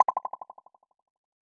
Echo Alert.wav